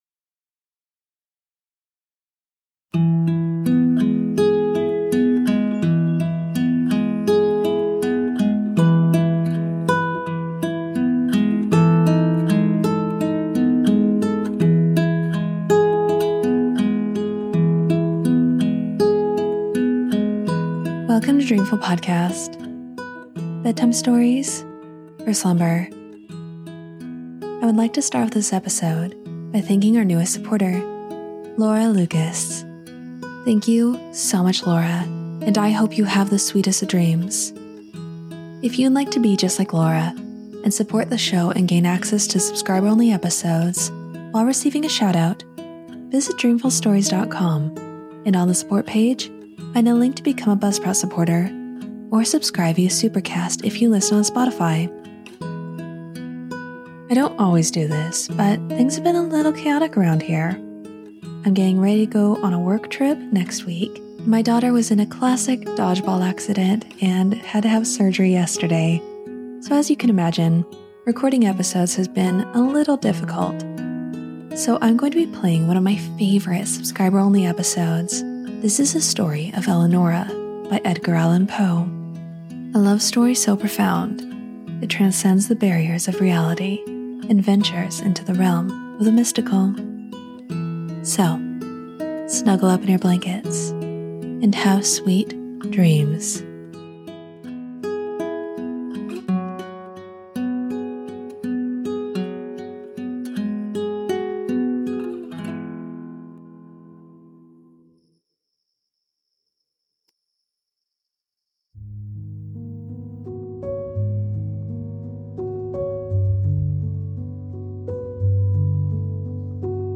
Slumber with a soothing retelling of Edgar Allan Poe’s Eleonora, moving from a hidden valley and first love to loss, departure, and an unexpected absolution that reframes a vow.